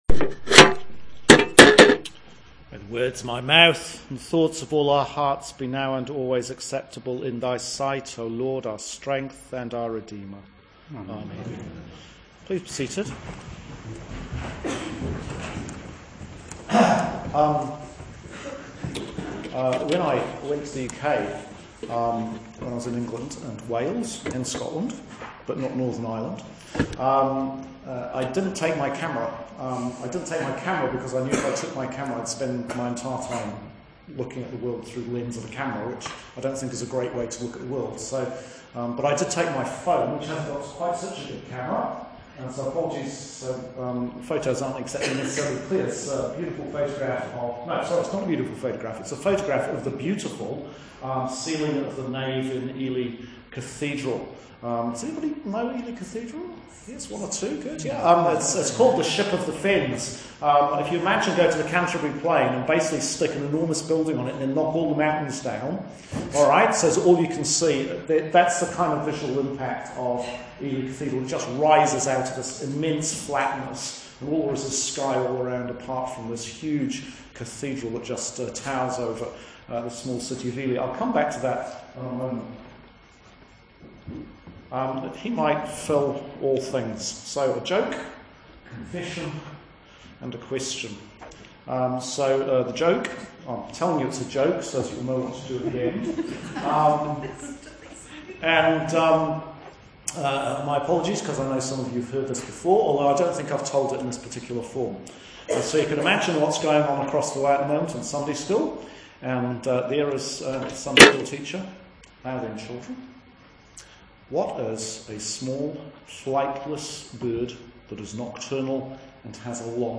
Sermon for 9th Sunday after Trinity – Year B – 2015